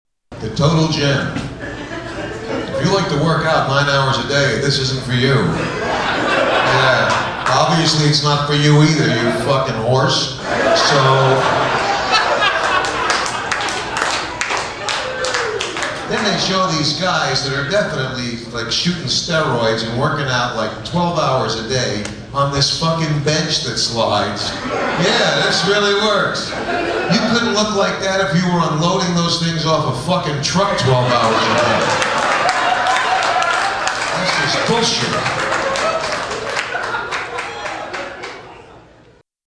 Tags: Comedian Robert Schimmel clips Robert Schimmel audio Stand-up comedian Robert Schimmel